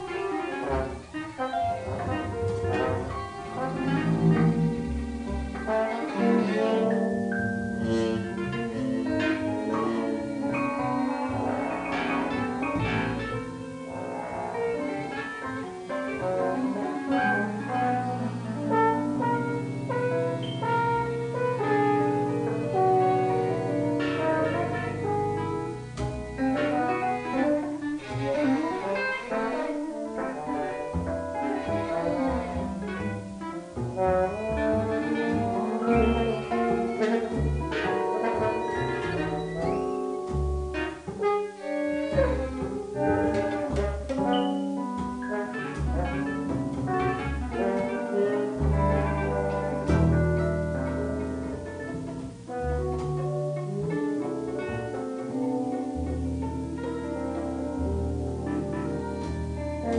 Apthsmthb (2005) any instruments